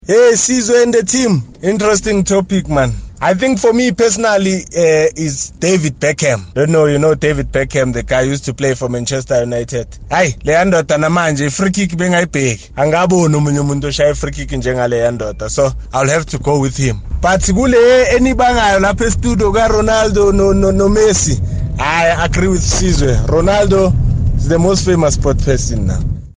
Kaya Drive listeners picked their most famous sporting personalities: